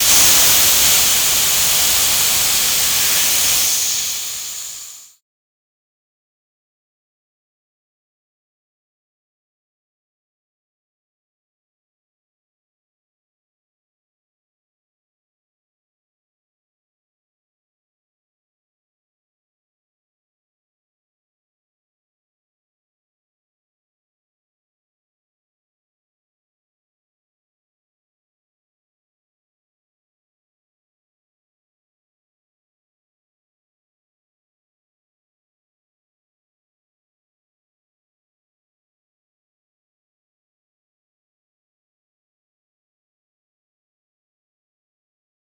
steam-offlet.ogg